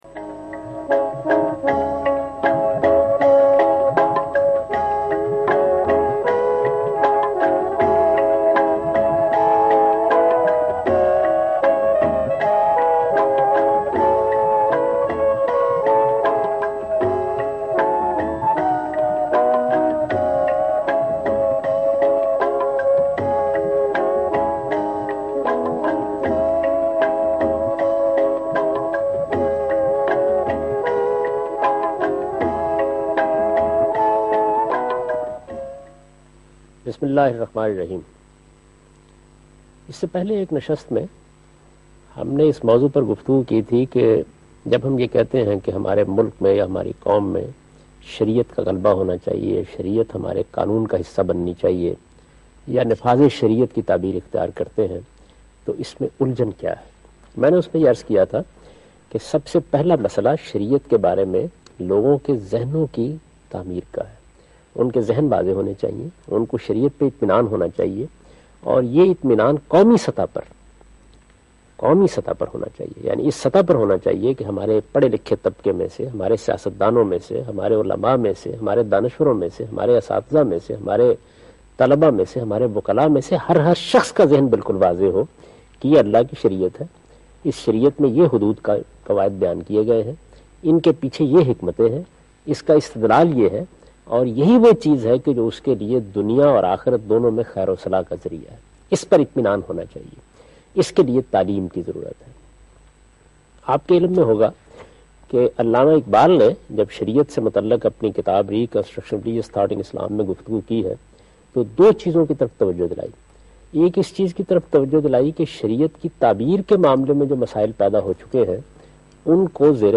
Discussion—on the topic' Pakistan mei Nifaz-e-Shariyat kyun aur kesey? 'by javed Ahmad Ghamidi- (Discussion aired on Ptv)